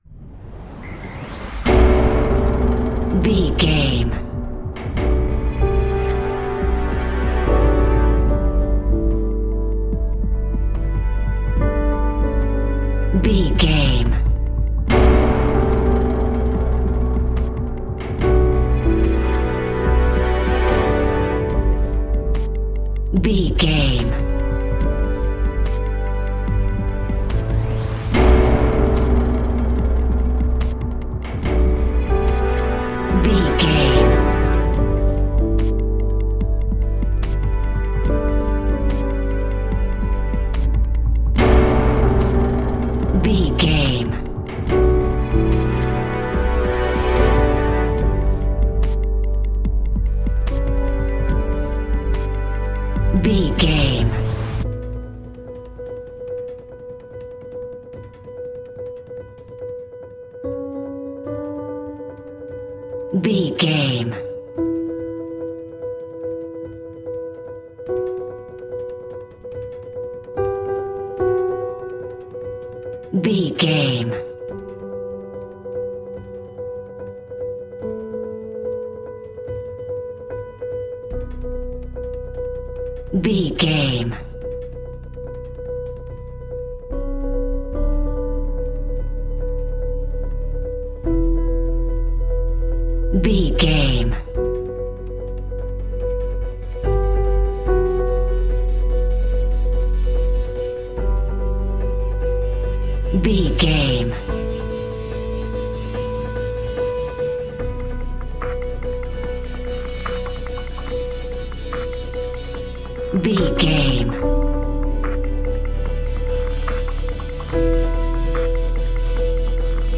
Ionian/Major
electronic
techno
trance
synthesizer
synthwave